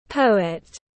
Nhà thơ tiếng anh gọi là poet, phiên âm tiếng anh đọc là /ˈpəʊət/.
Poet /ˈpəʊət/
Poet.mp3